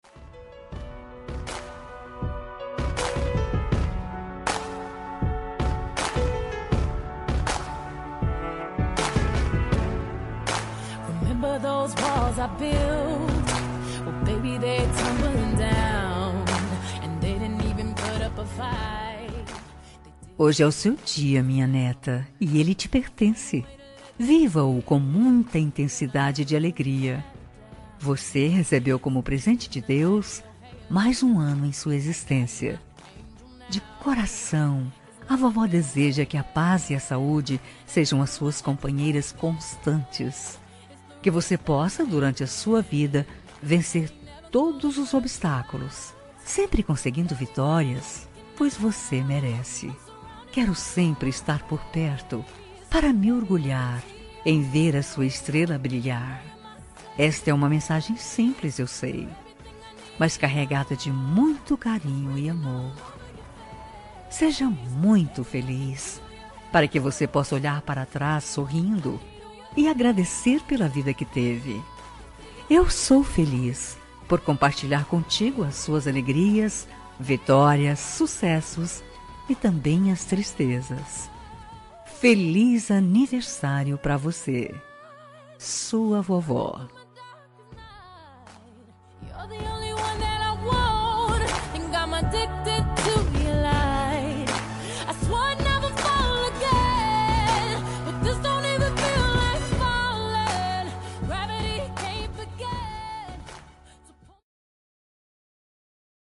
Aniversário de Neta – Voz Feminina – Cód: 131025